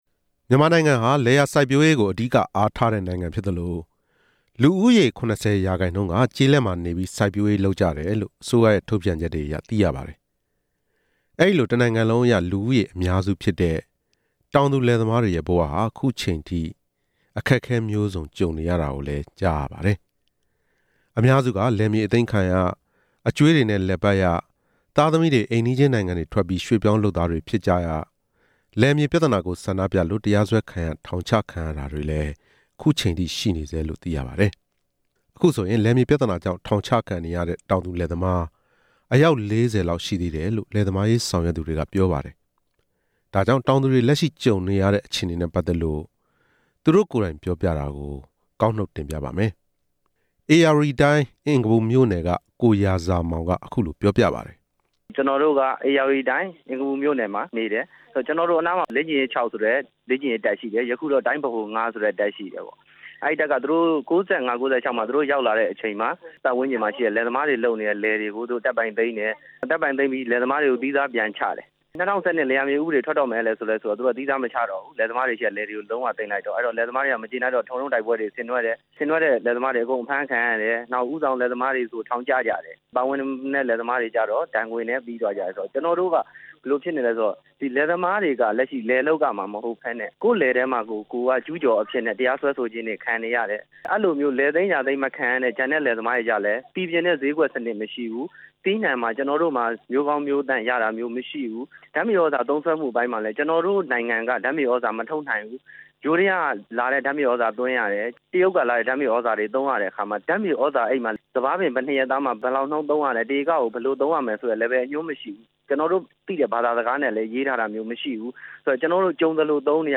တောင်သူလယ်သမားတွေရဲ့ ရင်ဖွင့်သံ